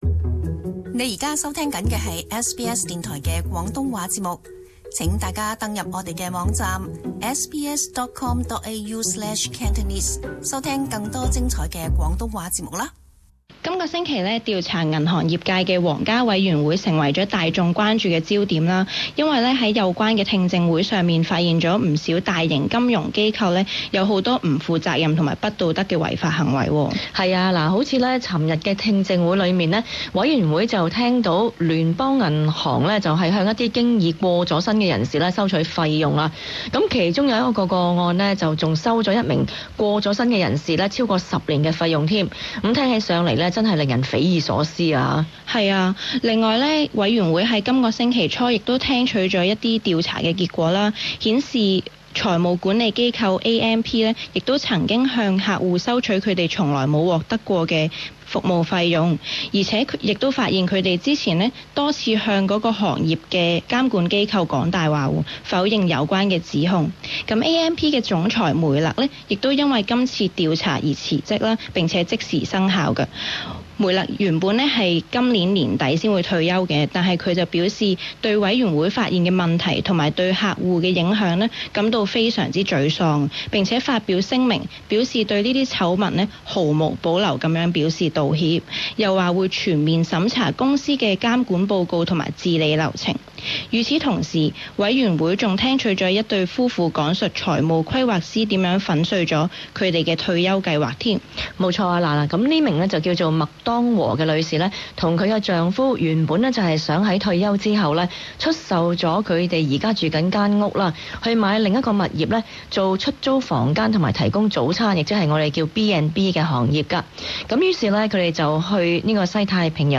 【時事報道】銀行家從事不當行為， 最高可被判入獄十年。